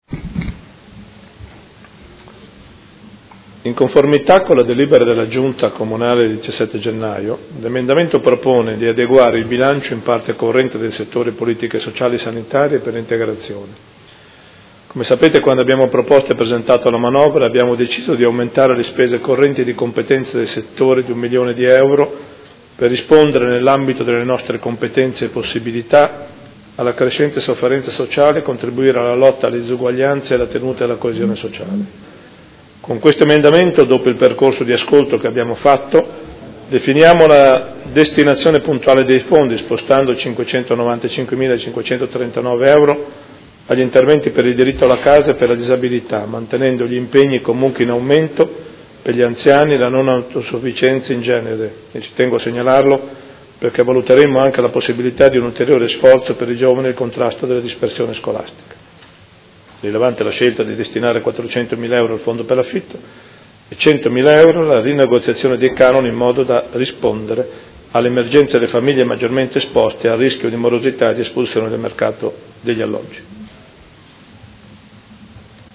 Seduta del 26 gennaio. Bilancio preventivo: emendamento n°9795